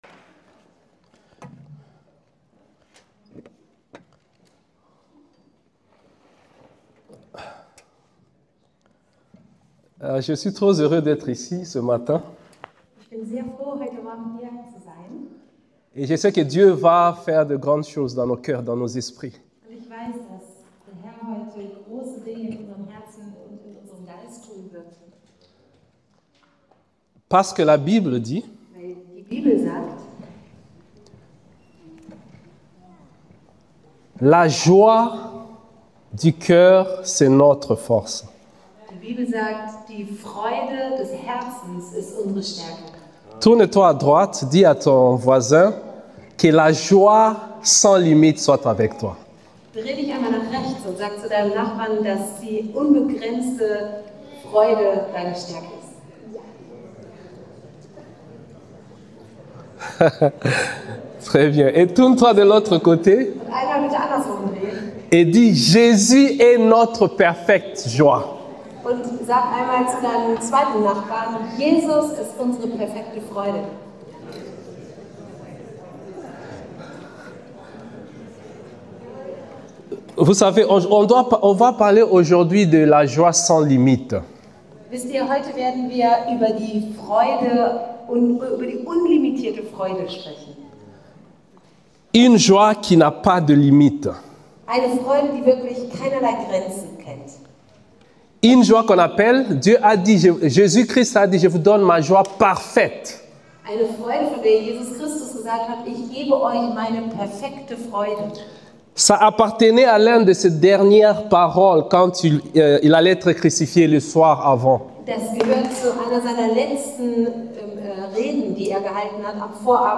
ICB- Predigtreihe